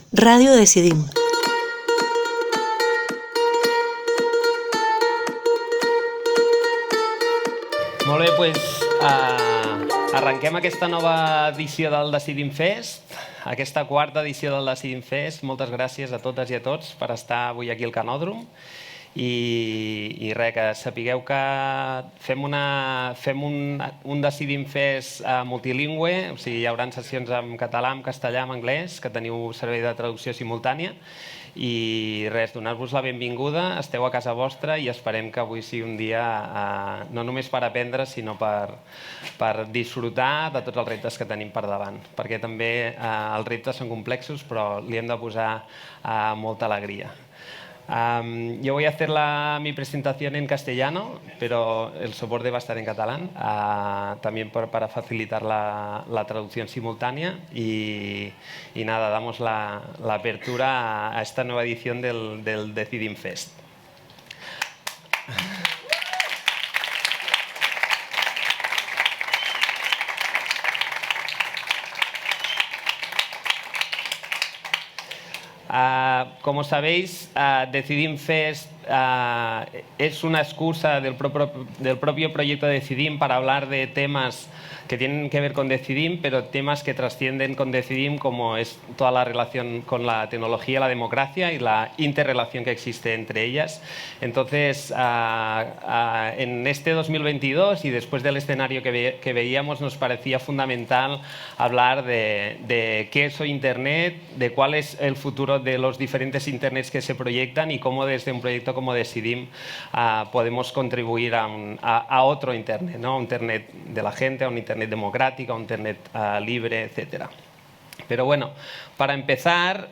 Presentación